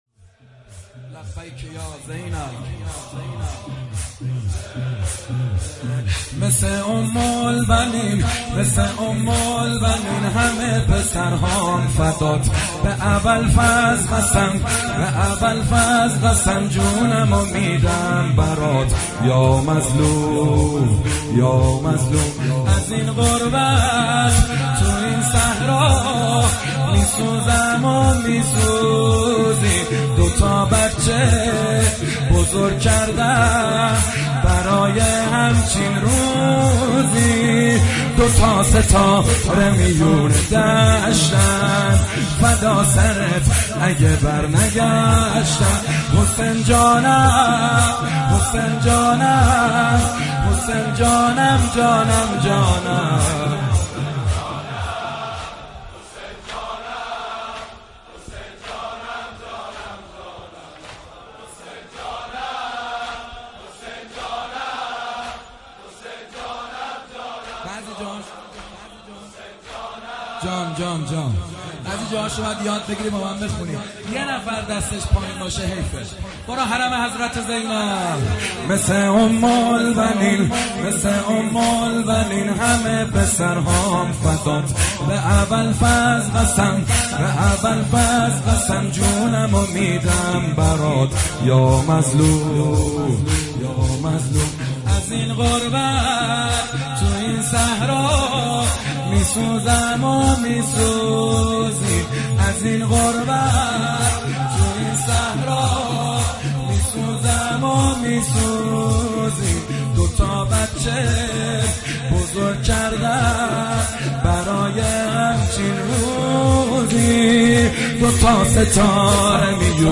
مثل ام البنین همه پسرهام فدات محمد حسین حدادیان شب چهارم محرم 96/07/2
نوحه جديد محمد حسین حدادیان, مداحی محرم حدادیان